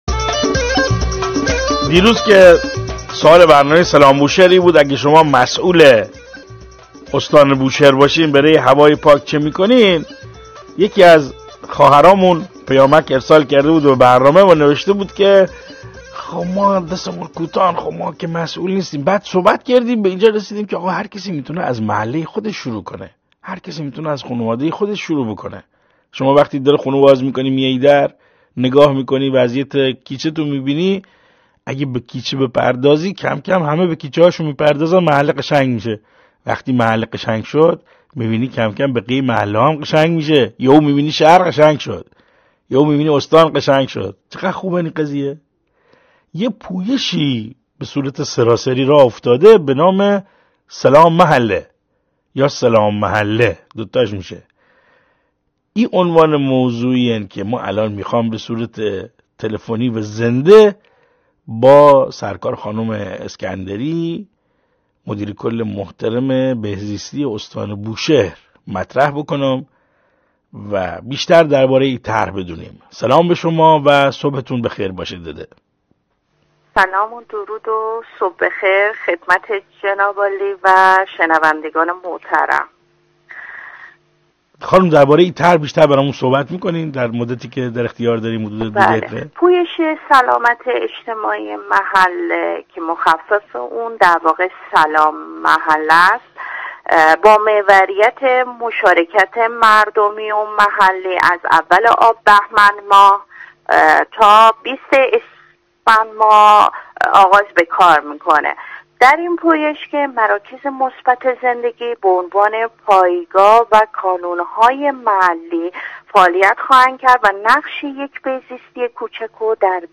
به گزارش روابط عمومی اداره کل بهزیستی استان بوشهر، مهرناز اسکندری مدیر کل بهزیستی استان در گفتگوی زنده با رادیو سلام بوشهر اعلام کرد: پویش «سلام محله» از ابتدای بهمن ماه تا بیستم همین ماه در استان بوشهر آغاز به کار کرده است.